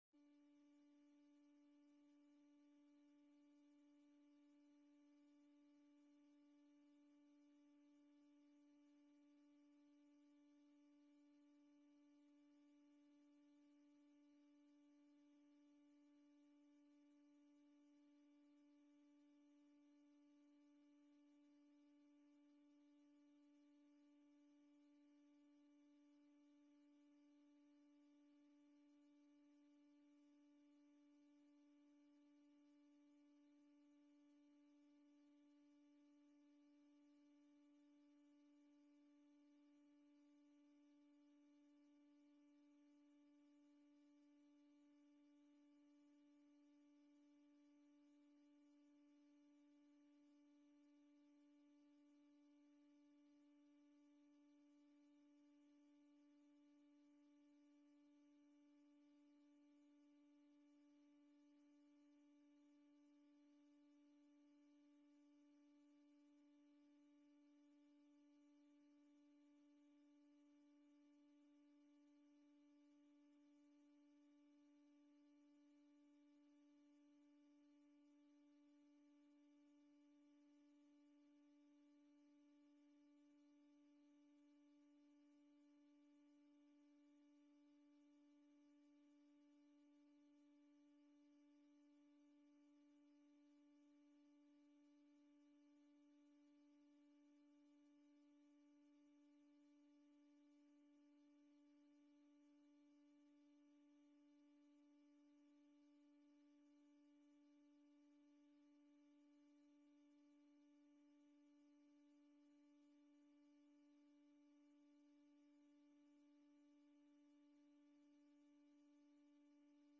Online, te volgen via livestreaming